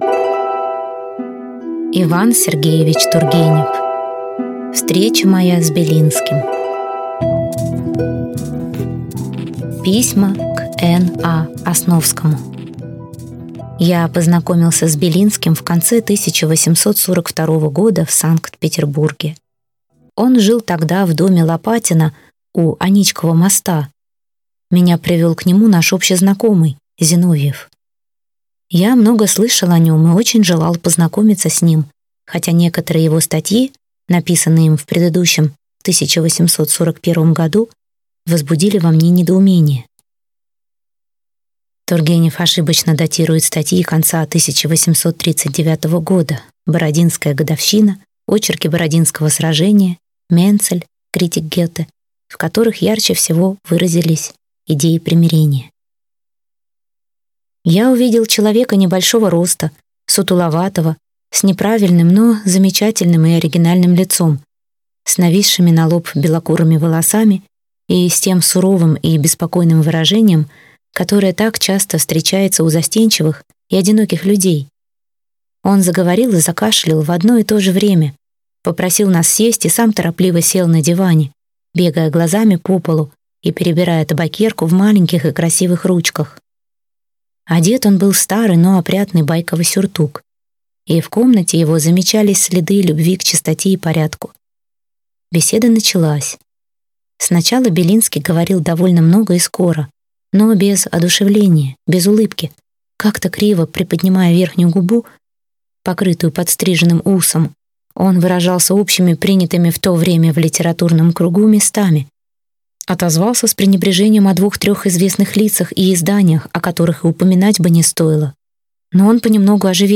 Аудиокнига Встреча моя с Белинским | Библиотека аудиокниг